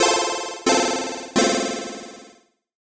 Jingle_Lose_01
8-bit 8bit Death Game Jingle Lose Nostalgic Old-School sound effect free sound royalty free Gaming